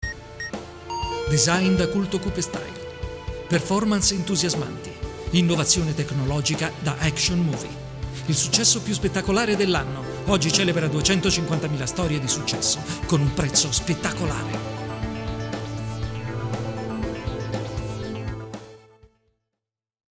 Speaker italiano, attore doppiatore, studio proprio, voice over, voce versatile, off-speaker
Sprechprobe: Industrie (Muttersprache):